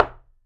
Wood Impact.wav